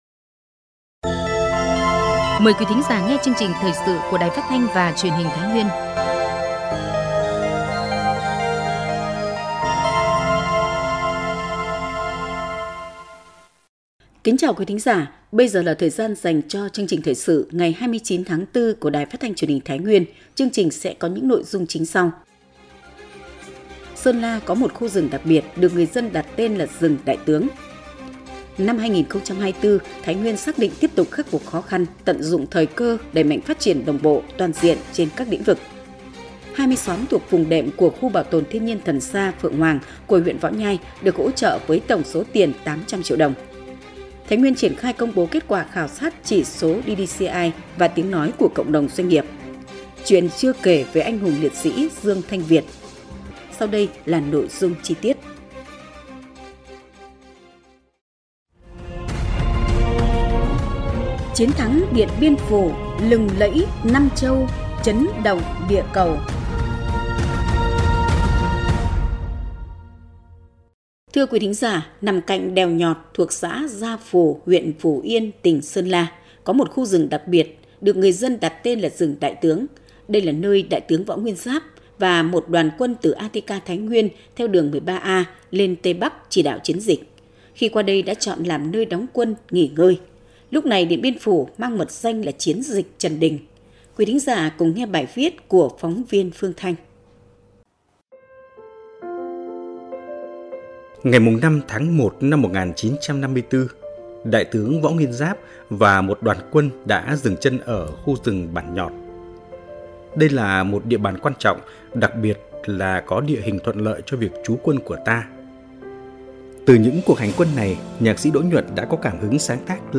Thời sự tổng hợp Thái Nguyên ngày 2/5/2024